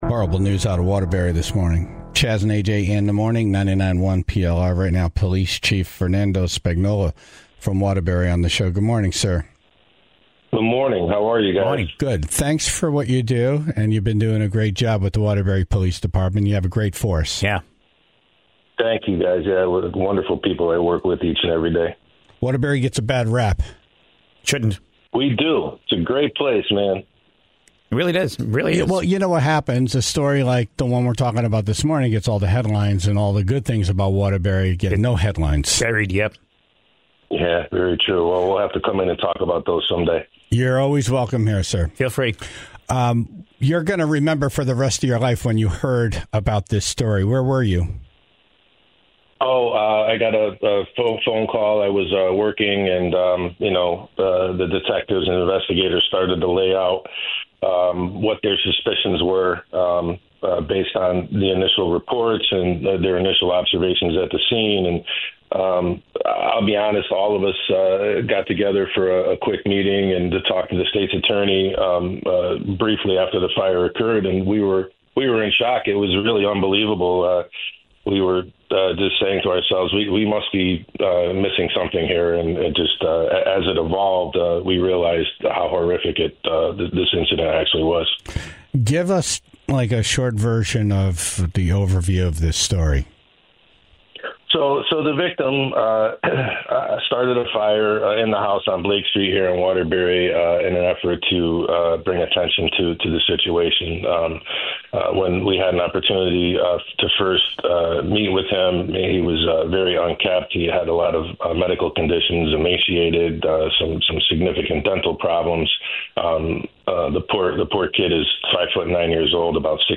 Waterbury Police Chief Fernando Spagnolo was on the phone